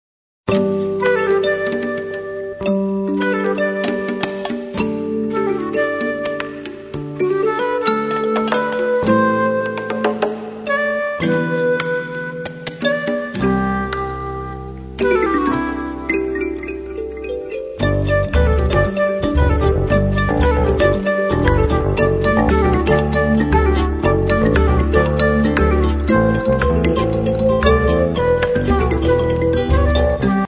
Our third CD was all taped (on the road)